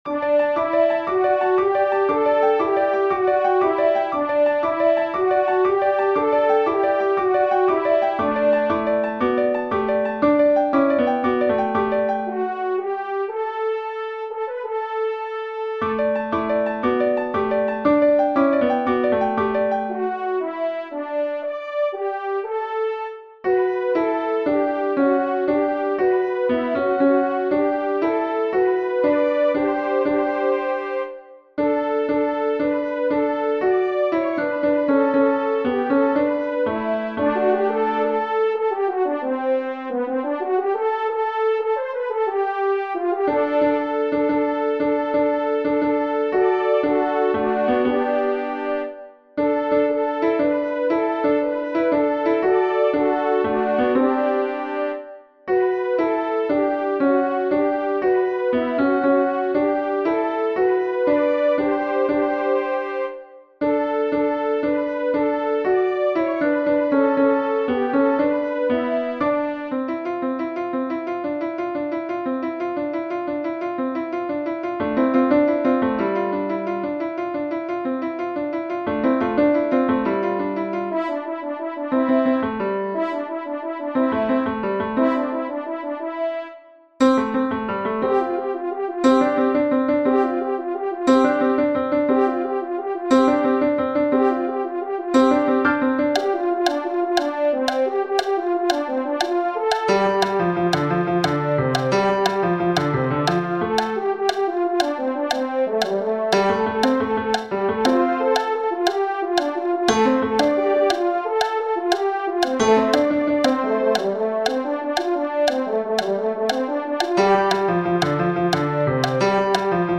These are loud. Turn your sound down before starting a recording.
Tenor Practice:
Tenor part sounds like a piano. The clicking noise is the choir clapping.
alleluia_by_basler-tenor-1.mp3